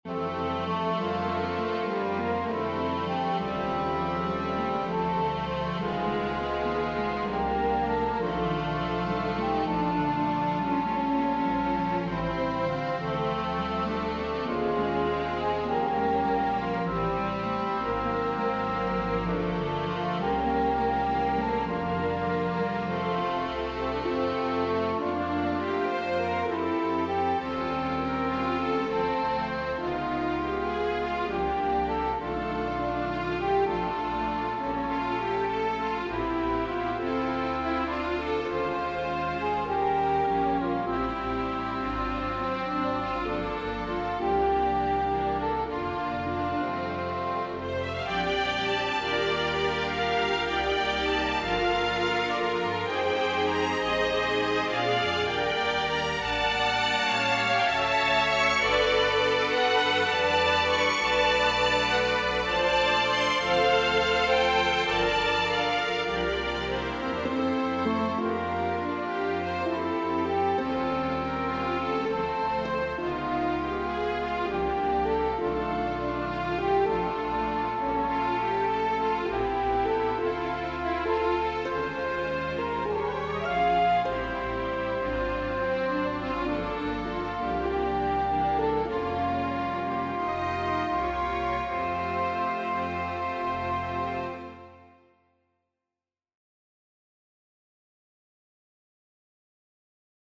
1st violins, 2 violins, violas, cellos, basses .